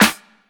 Game Snare4.wav